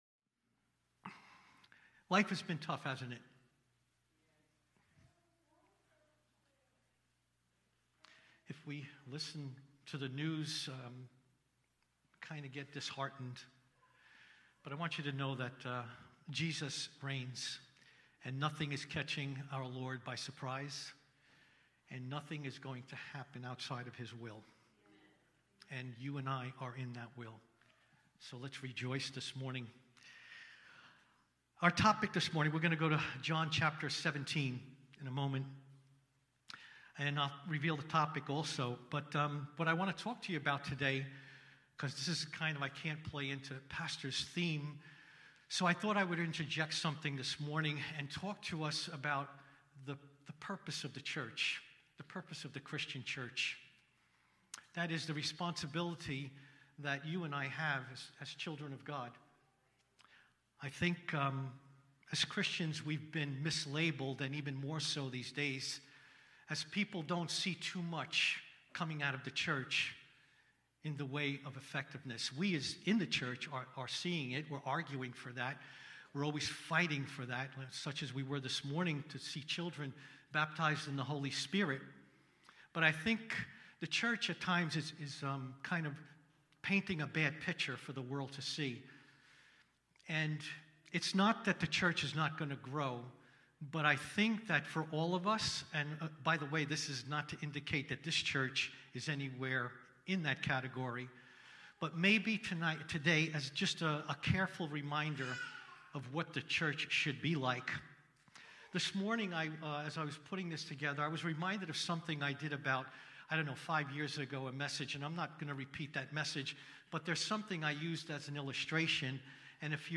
Cornerstone Fellowship Sunday morning service, livestreamed from Wormleysburg, PA.